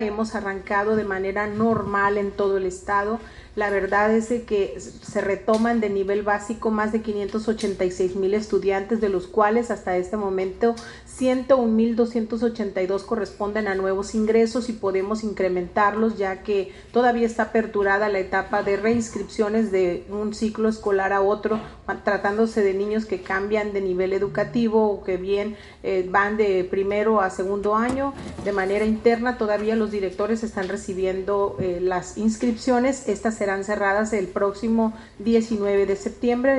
Durante la Conferencia de Prensa, Vocería del Gobierno del Estado de Sinaloa, presidida por el Secretario General de Gobierno, Feliciano Castro Meléndrez, y donde participaron también la Vocera de Seguridad, Verona Hernández y el Secretario de Salud, Cuitláhuac González Galindo, la Titular de la SEPyC destacó que este ciclo lectivo arrancó de manera normal en todo el estado el pasado lunes 01 de septiembre.